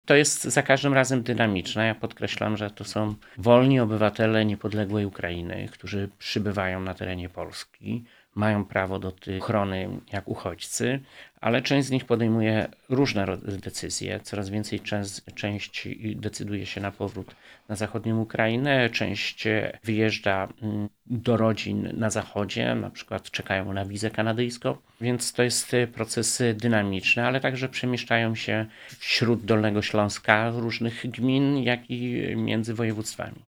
Mówi Jarosław Obremski – wojewoda dolnośląski.